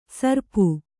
♪ sarpu